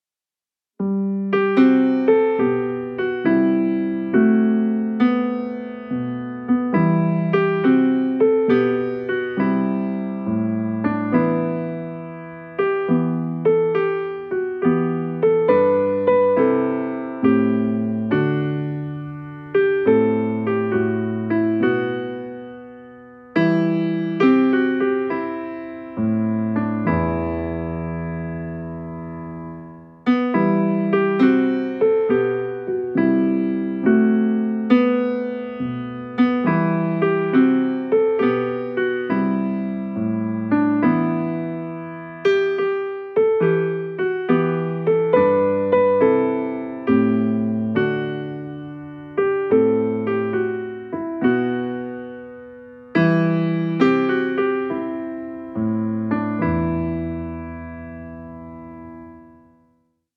interprétés dans une atmosphère chaleureuse et raffinée.
empreint de douceur et de recueillement